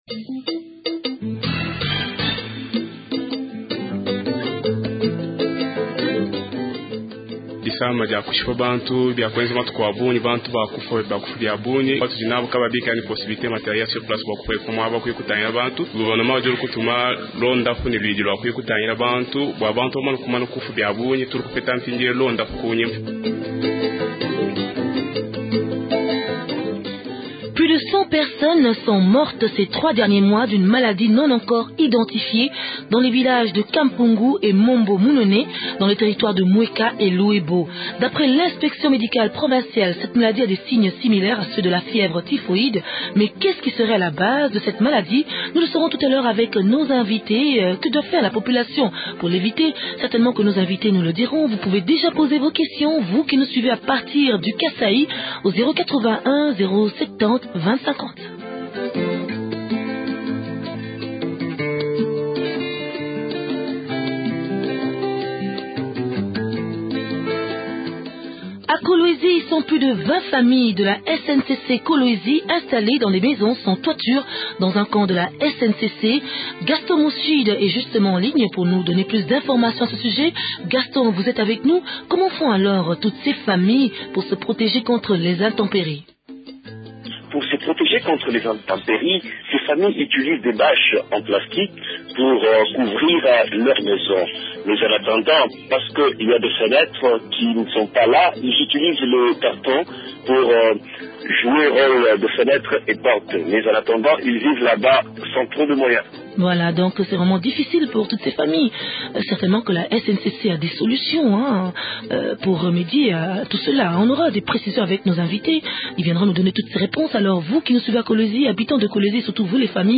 Le point dans cet entretien avec Dr Ntumba Tshitoka, ministre provincial de la santé.